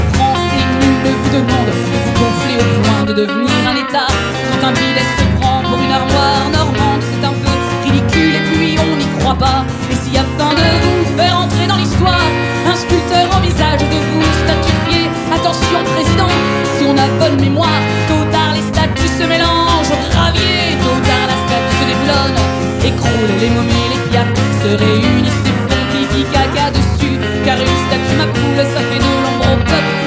Enregistré en public
Chansons francophones